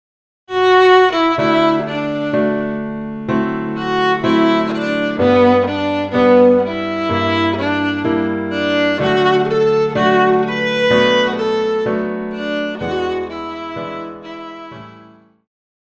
Pop
Viola
Band
Instrumental
Rock,Country
Only backing